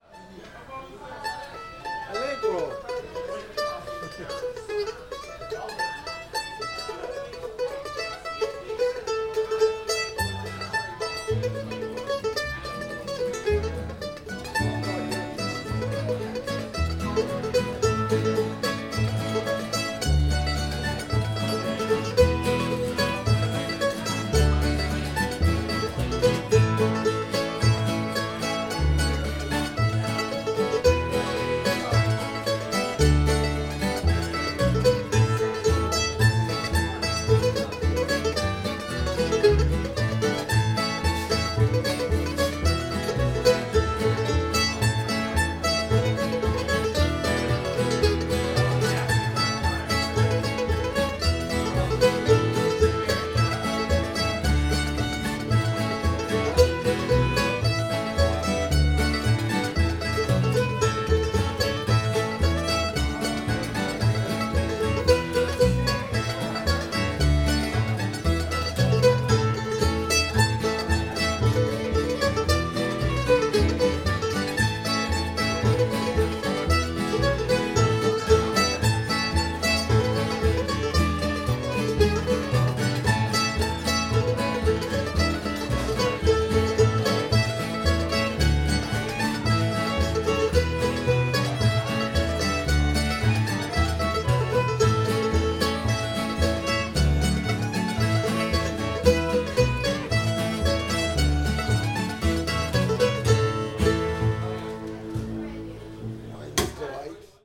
wine's delight [A modal]